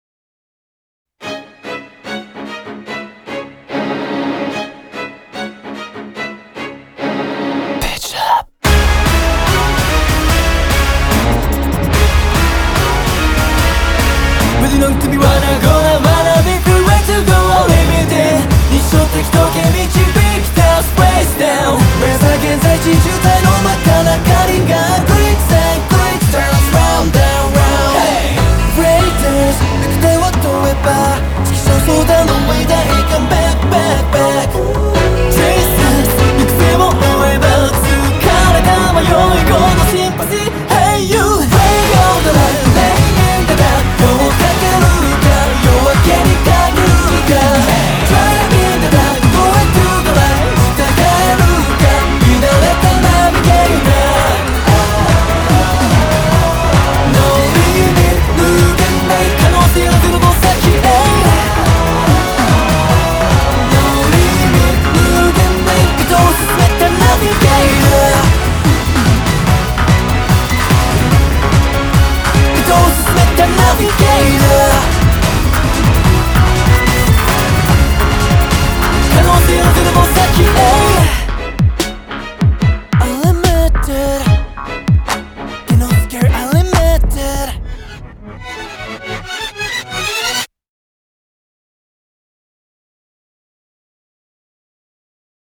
BPM73-146
MP3 QualityMusic Cut